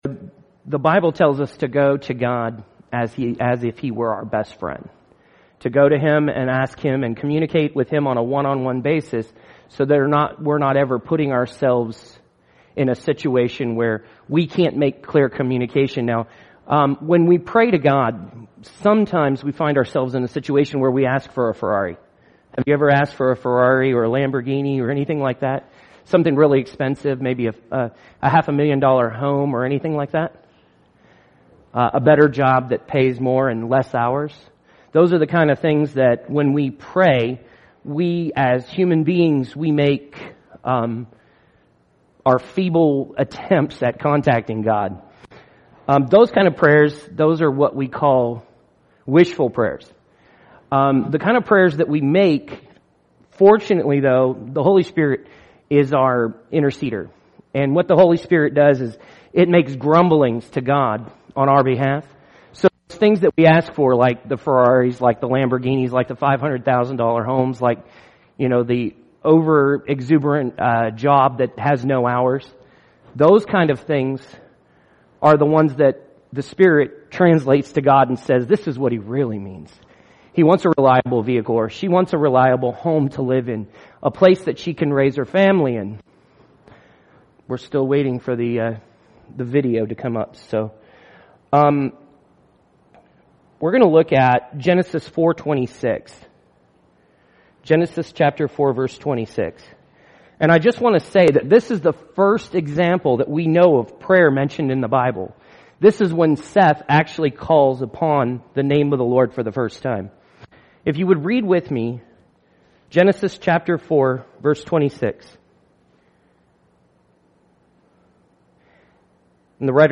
Why Pray (Bible Study)